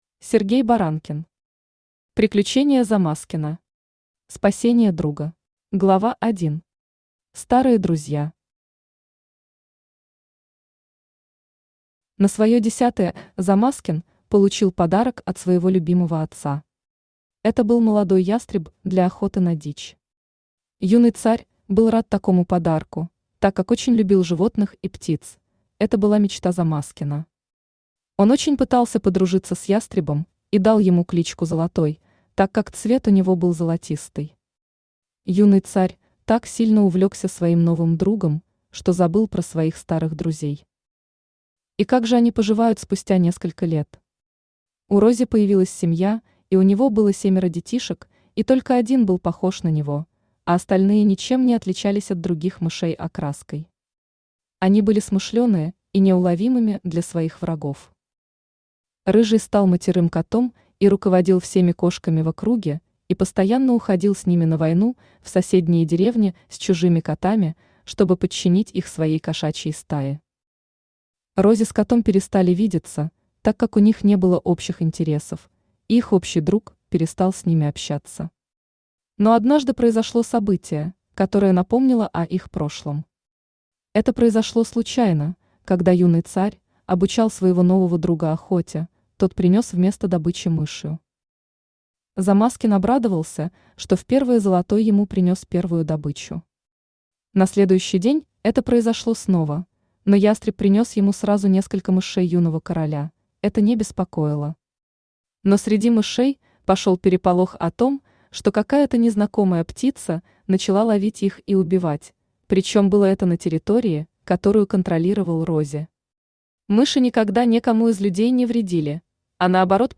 Автор Сергей Валентинович Баранкин Читает аудиокнигу Авточтец ЛитРес.